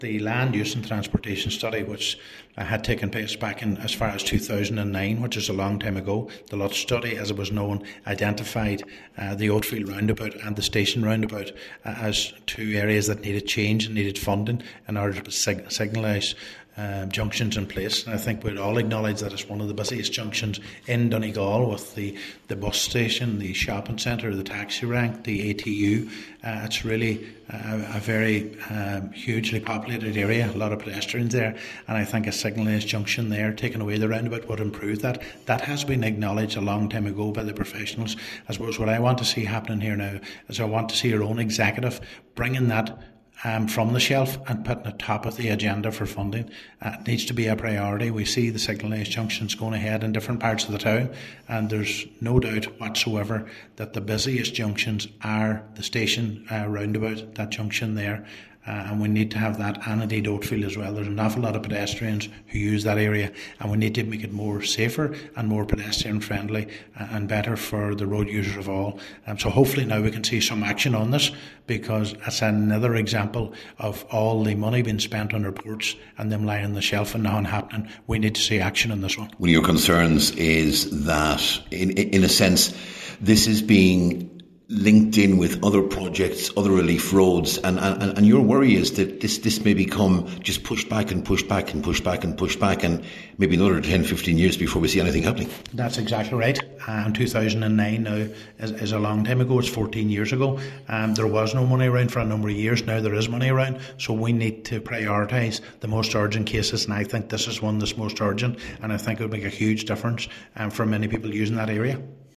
Cllr Ciaran Brogan asked if funding has already been applied for, and also expressed concern that linking this with other planned projects may delay the plans even further.
He added that given the heavy footfall, it must be treated as a priority: